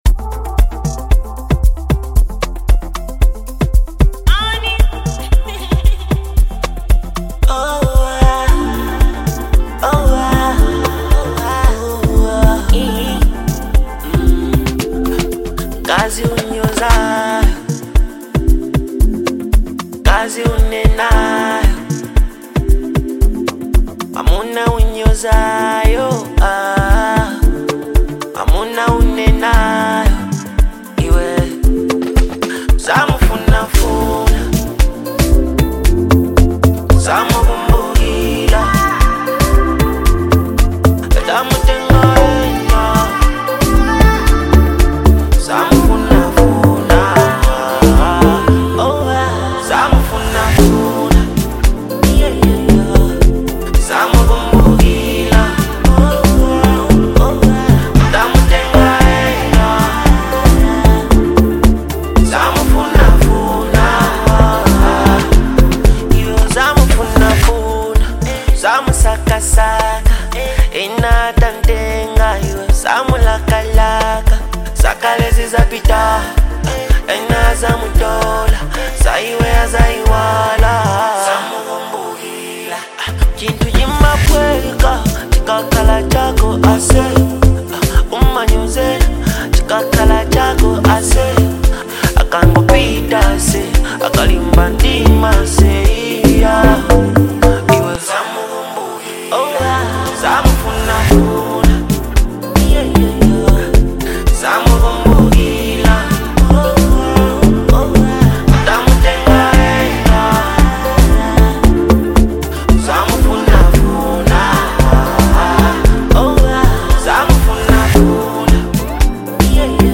Genre : Afro Beat
emotional and melodic banger